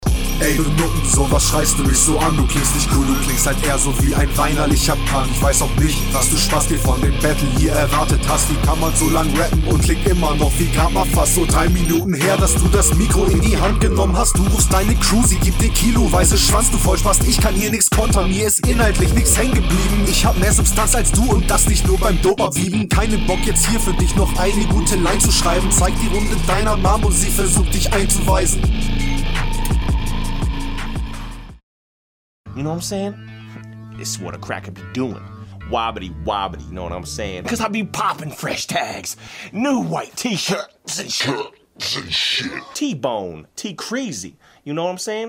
Der viel ruhigere Stimmeinsatz kommt somehow viel besser auf den Beat.